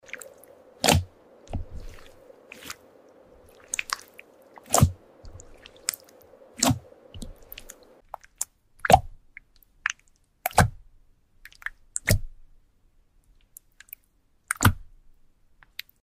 Fishbowl Slime! | ASMR